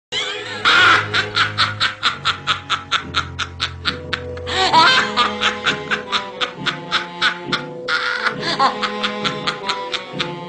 Risada Bruxa Branca De Neve
Risada da velha bruxa do desenho Branca De Neve (Disney).
risada-bruxa-branca-de-neve.mp3